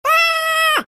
Scream! Roblox Botão de Som
Games Soundboard0 views